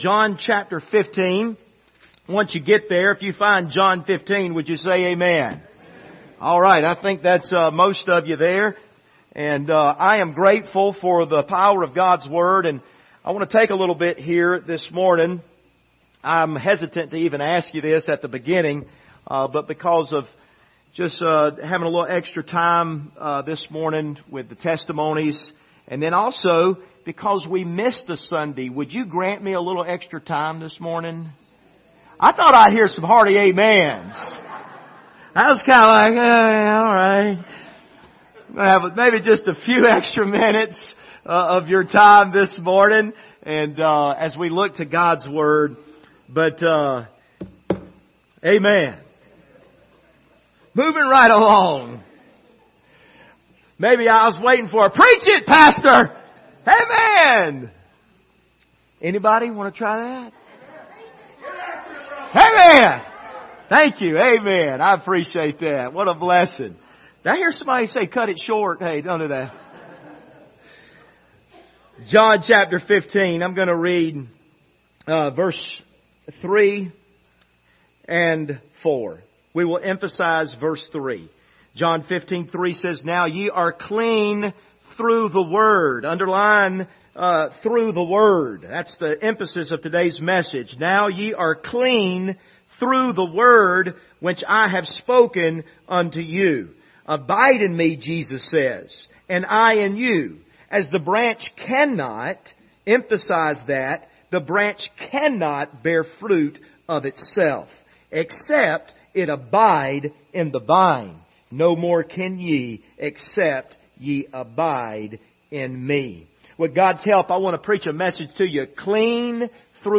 John 15:3-4 Service Type: Sunday Morning Topics: Word of God